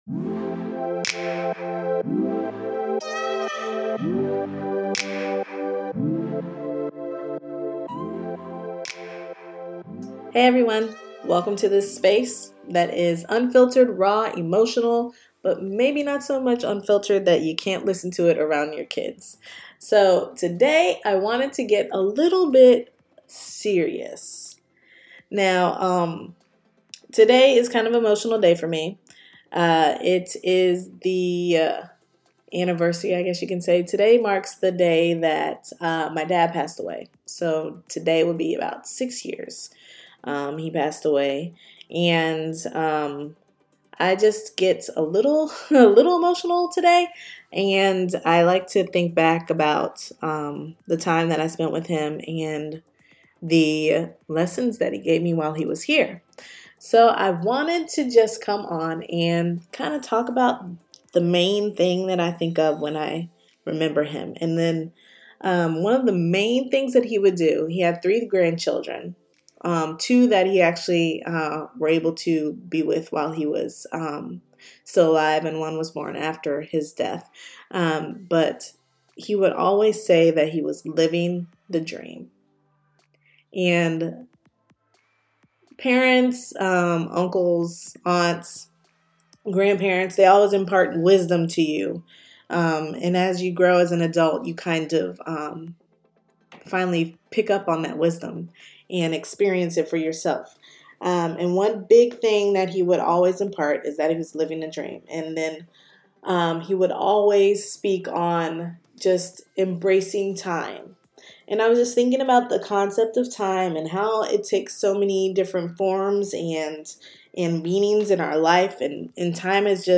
So, I’m kinda having fun recording these voice-only “episodes”.
This is unfiltered and unscripted, recorded in one take – with the lump in my throat and tears rolling down my face.